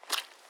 Water Walking 1_01.wav